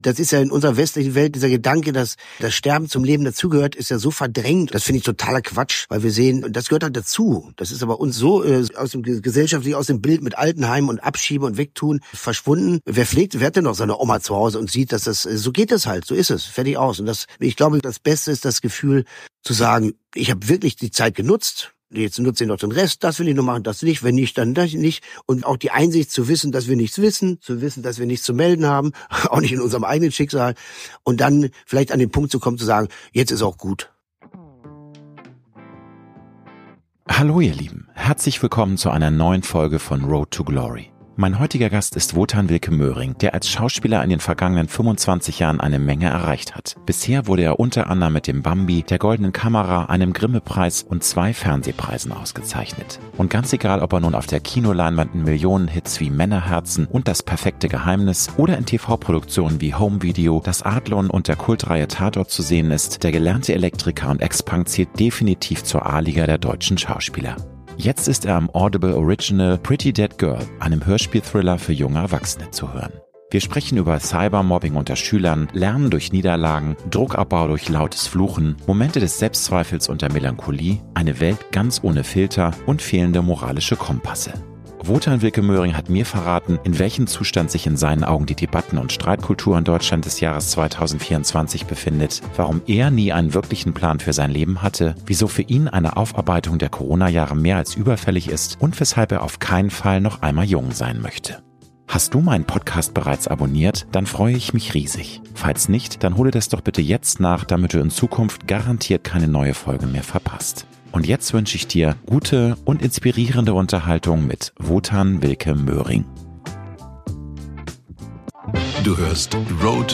Beschreibung vor 2 Jahren Mein heutiger Gast ist Wotan Wilke Möhring, der als Schauspieler in den vergangenen 25 Jahren eine Menge erreicht hat: Bisher wurde er u.a. mit dem Bambi, der Goldenen Kamera, einem Grimme-Preis und zwei Fernsehpreisen ausgezeichnet.